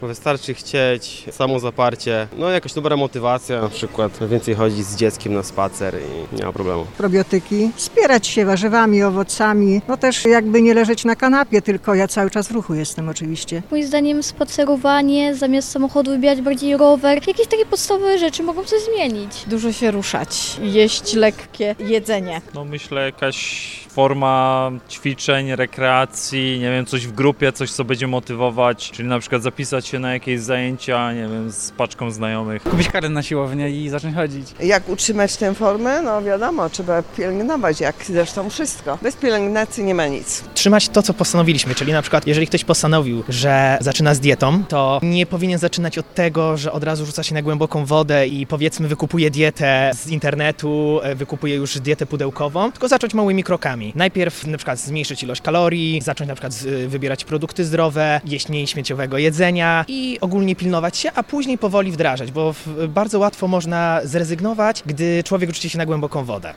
Co jeszcze można zrobić, aby wrócić do dobrej formy? Zapytaliśmy Dolnoślązaków.